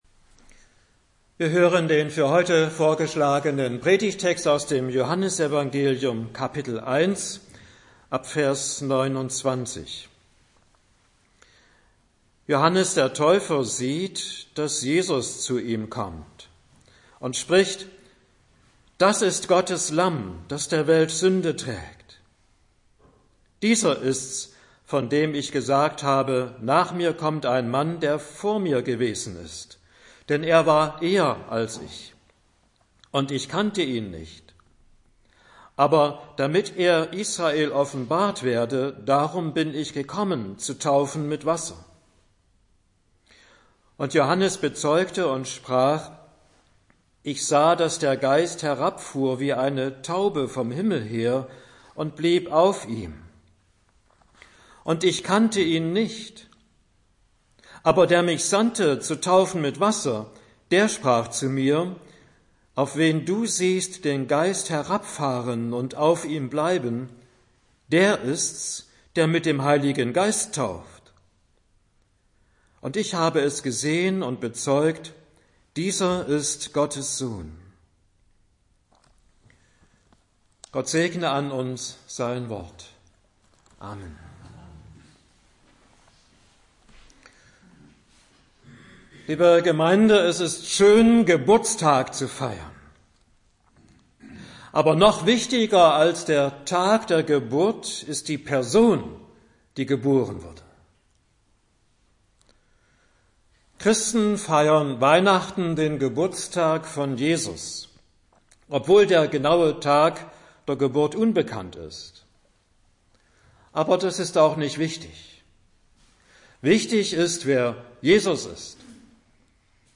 Predigt für den 1. Sonntag nach Epiphanias